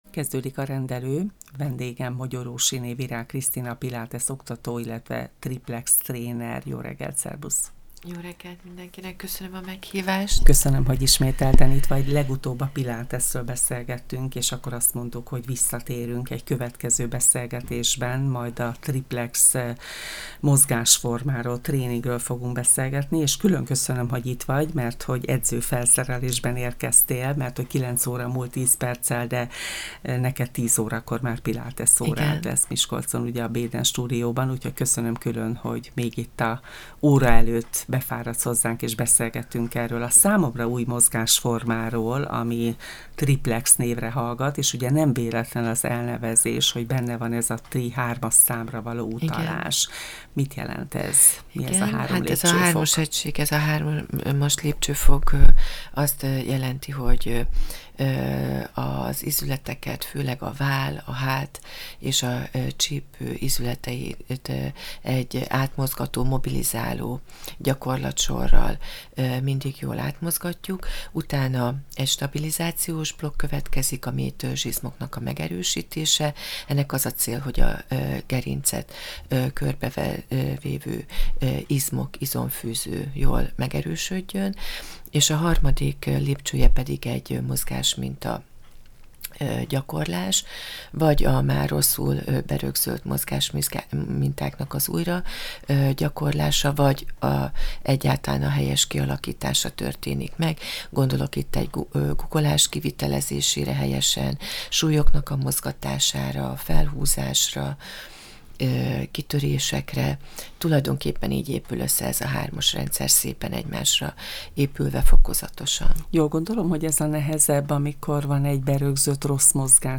A Triplex tréningről beszélgettünk a Csillagpont Rádió Rendelő című magazinműsorában